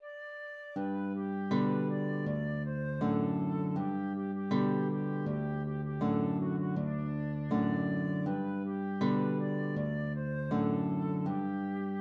g4 } \relative c' { \time 2/4 \tempo 4=80 \partial 4 \key g \major \set Staff.midiInstrument="flute" d'4^\fermata